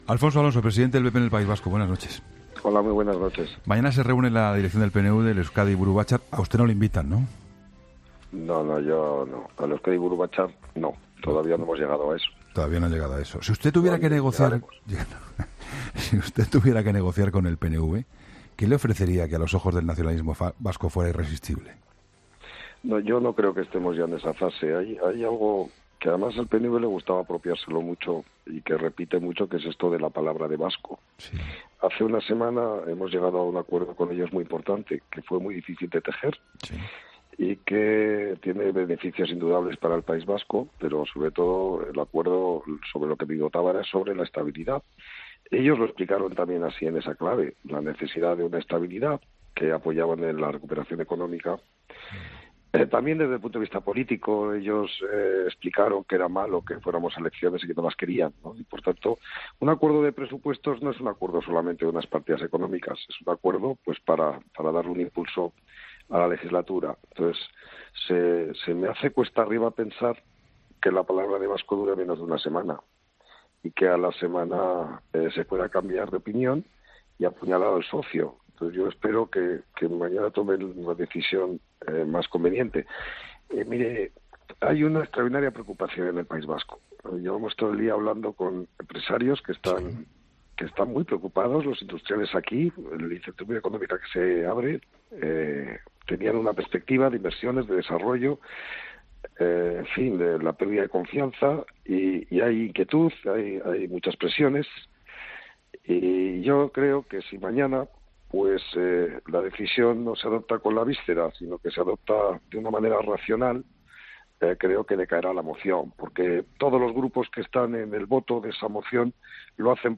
El presidente del PP en el País Vasco, Alfonso Alonso, ha atendido a 'La Linterna' de Juan Pablo Colmenarejo horas antes del debate de la moción de...